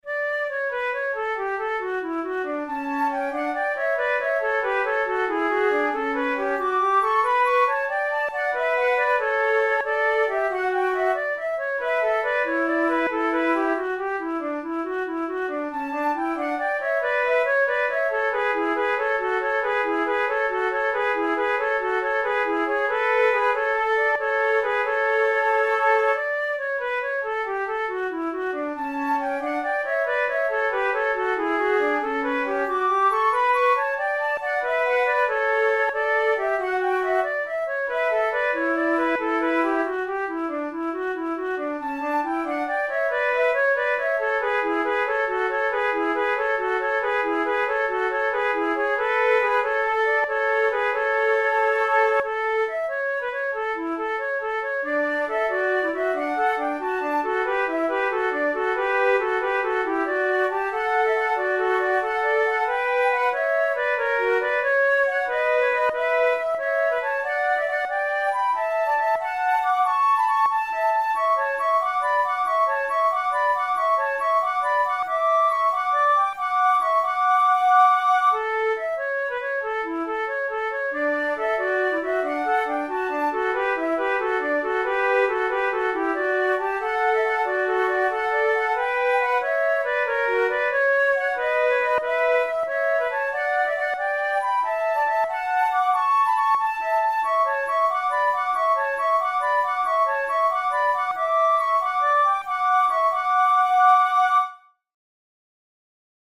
by Johann Pachelbel, arranged for two flutes
Everybody knows Pachelbel's Canon, but the Gigue that originally accompanied it never received the same amount of popularity, even though it is a lively and energetic dance.
Categories: Baroque Jigs Difficulty: intermediate